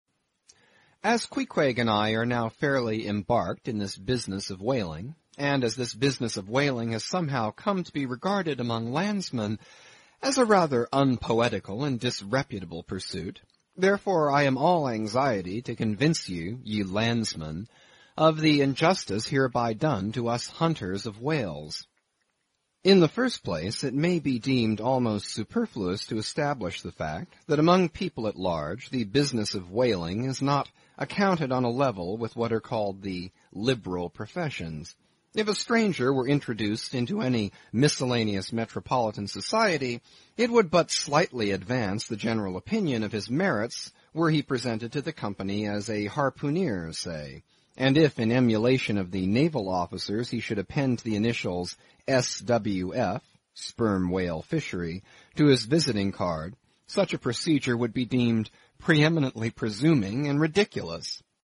英语听书《白鲸记》第130期 听力文件下载—在线英语听力室